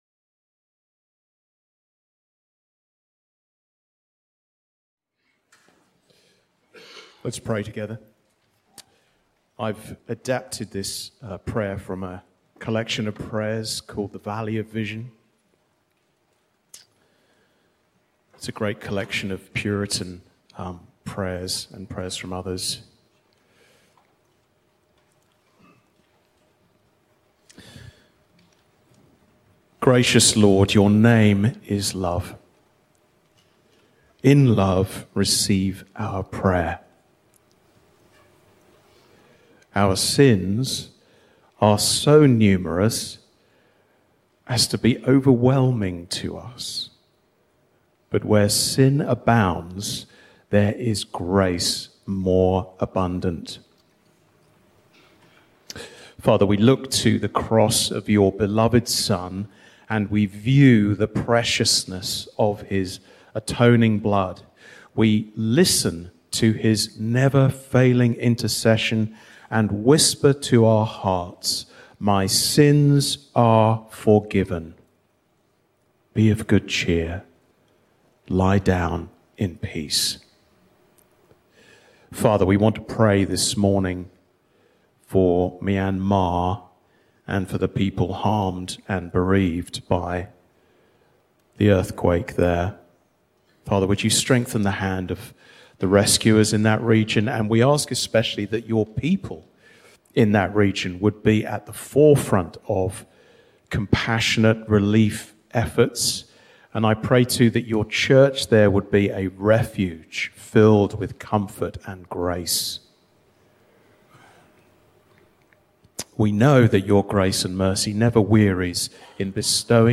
Counter Cultural Thanksgiving Sermons Christ Community Church: Daytona Beach, FL podcast